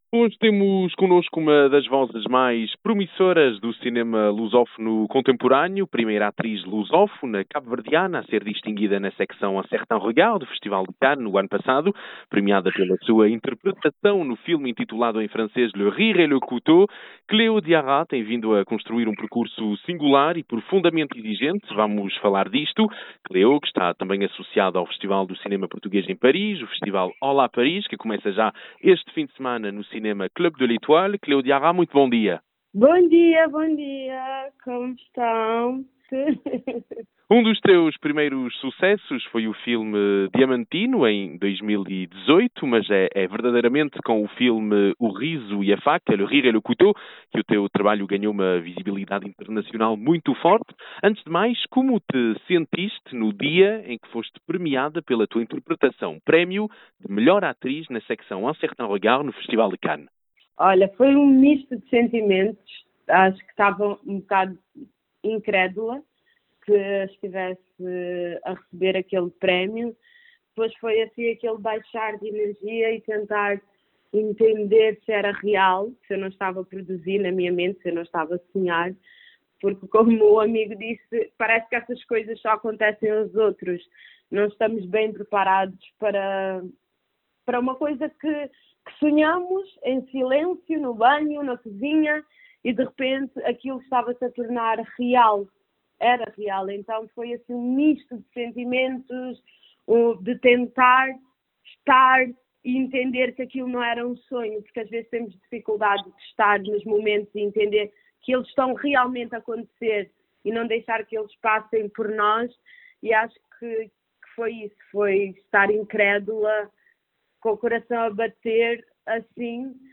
Entrevista-Cleo-Diara.mp3